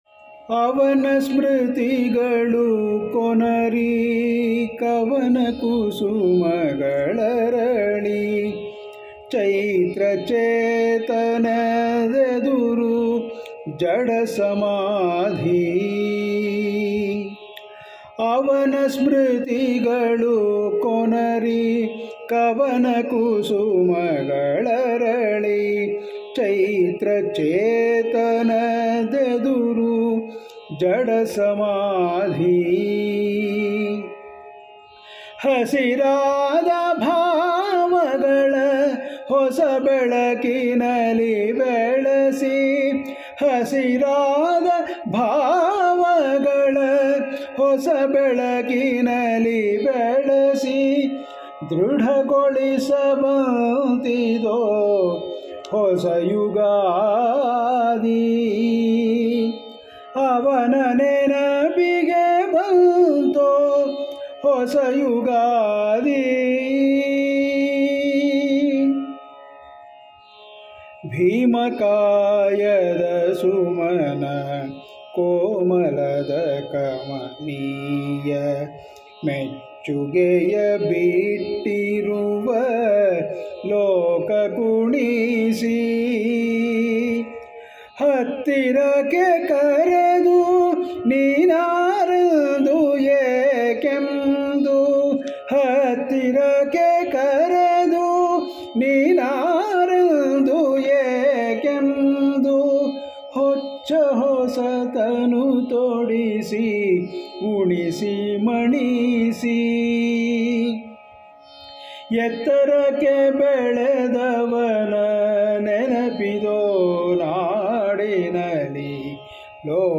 Patriotic Songs Collections
Solo